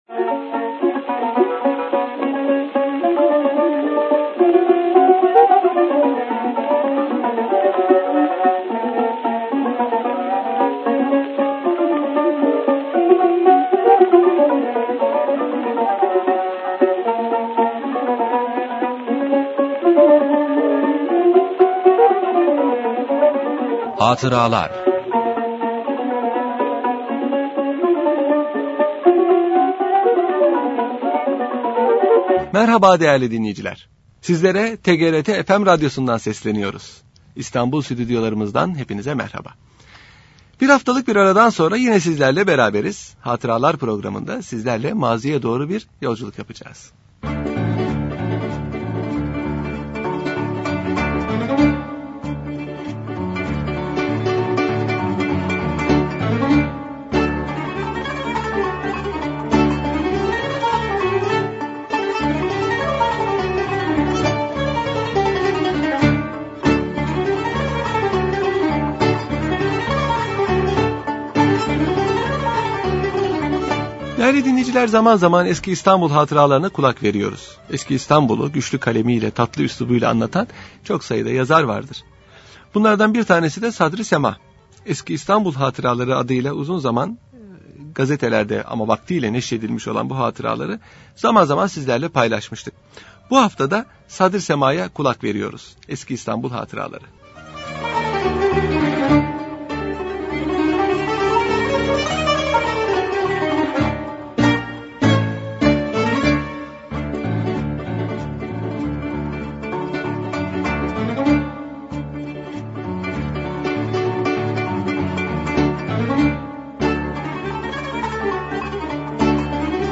Radyo Programi - Sadri Sema -Görücü -Köprü-Deniz hamamı